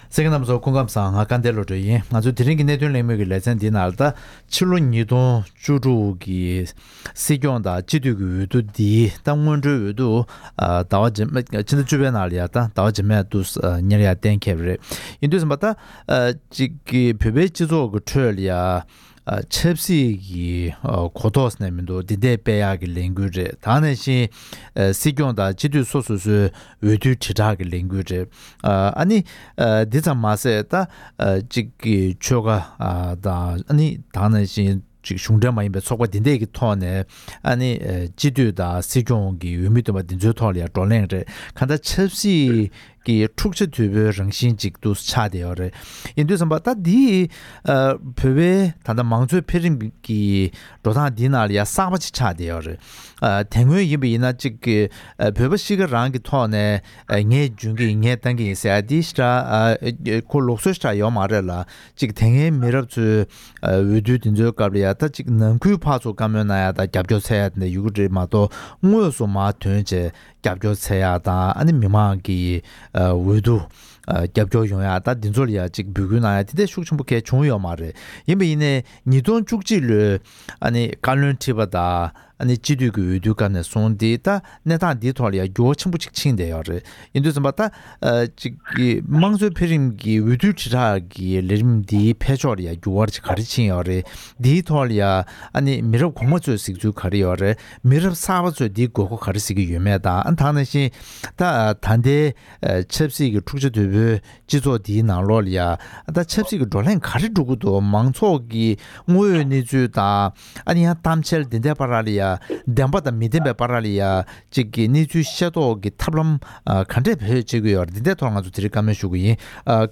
འབྱུང་འགྱུར་སྲིད་སྐྱོང་དང་སྤྱི་འཐུས་ཀྱི་འོས་བསྡུ་དེ་བཞིན་དམངས་གཙོ་འཕེལ་རིམ་གྱི་འོས་བསྡུའི་དྲིལ་བསྒྲགས་ཀྱི་ལས་རིམ་སྤེལ་ཕྱོགས་སོགས་ཀྱི་ཐད་བཀའ་མོལ་ཞུས་པ།